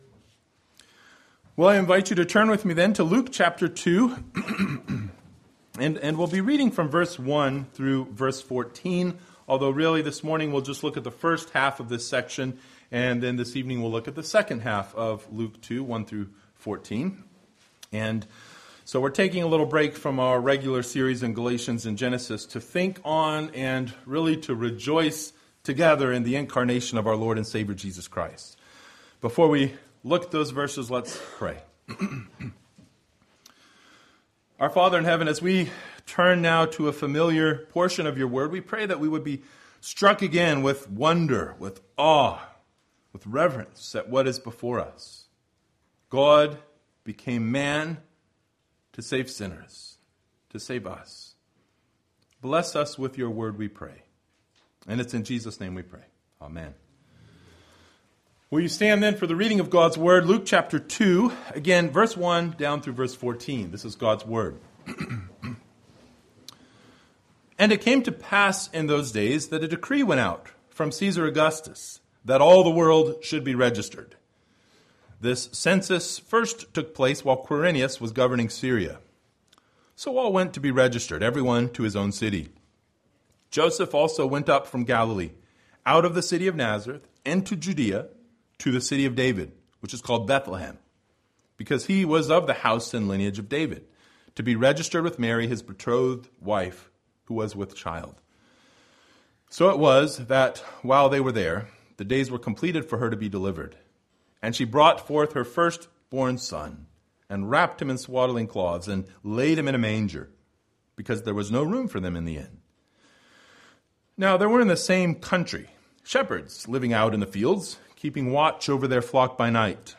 Passage: Luke 2:1-14 Service Type: Sunday Morning